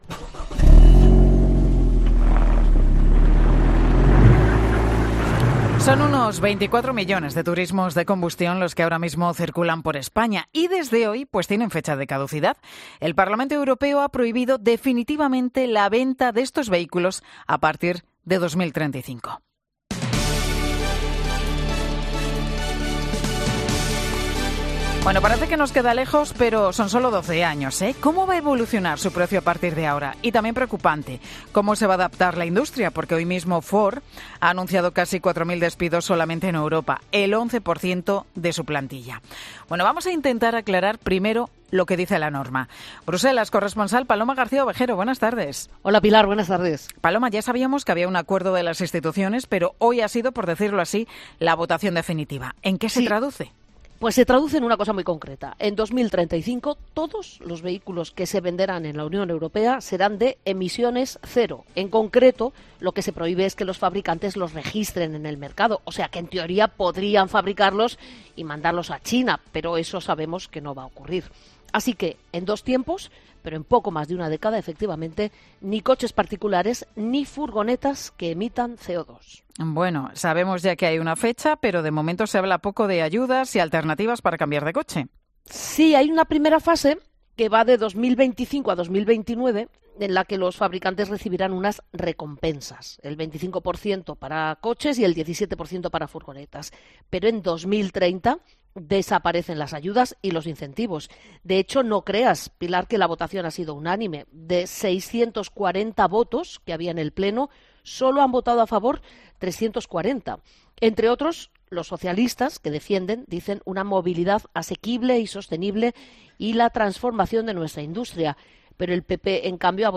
Paloma García Ovejero cuenta en Mediodía COPE los detalles de la prohibición